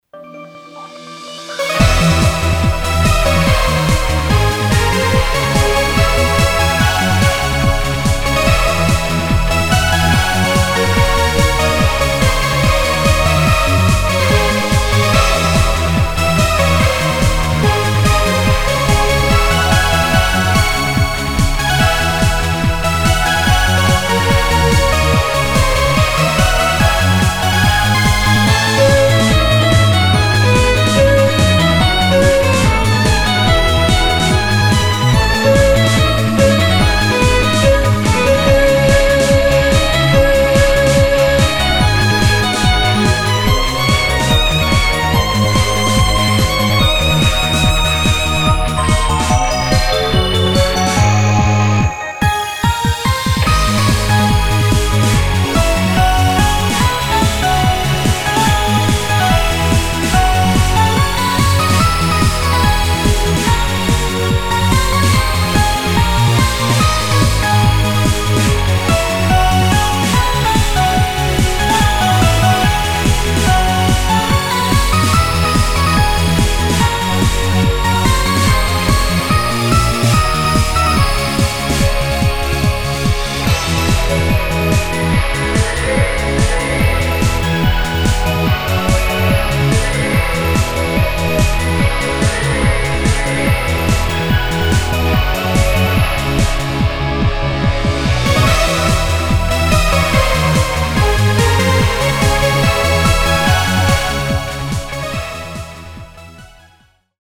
フリーBGM バトル・戦闘 4つ打ちサウンド
フェードアウト版のmp3を、こちらのページにて無料で配布しています。